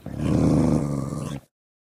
Minecraft / mob / wolf / growl1.ogg
growl1.ogg